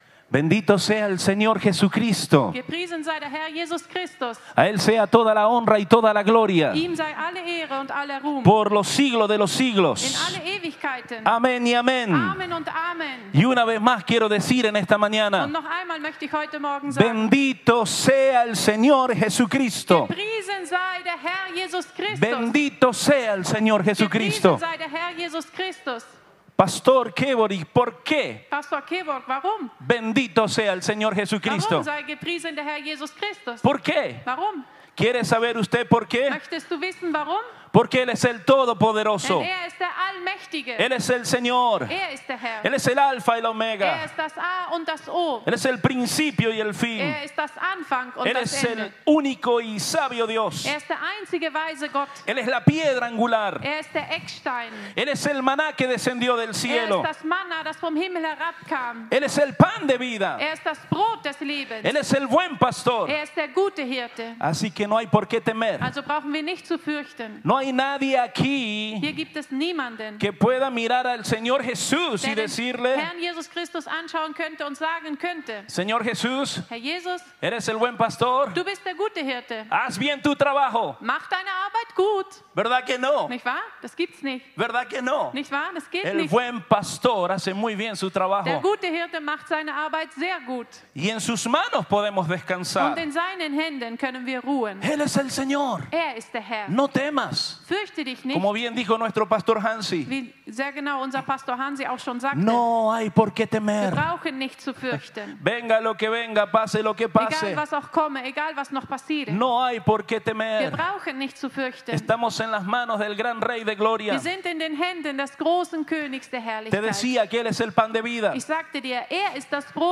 Predigt
im Christlichen Zentrum Villingen-Schwenningen.